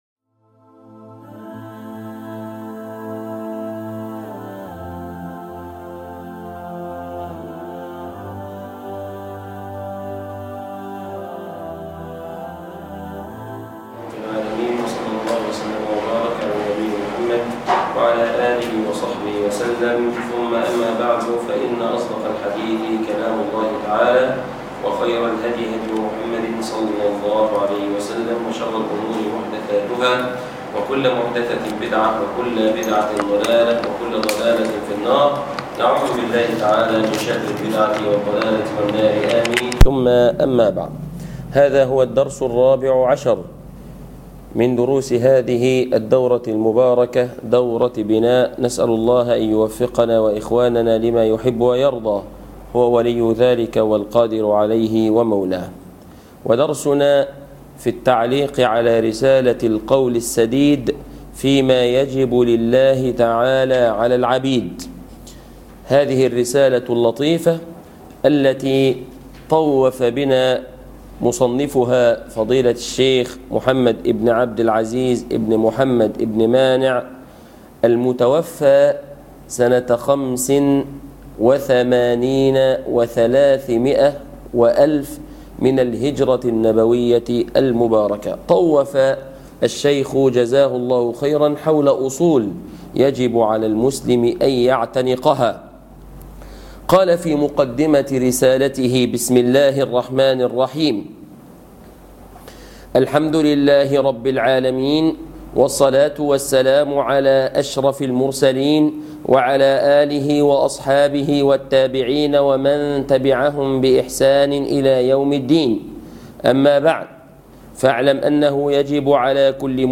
الدرس الرابع عشر ( القول السديد فيما يجب لله تعالى على العبيد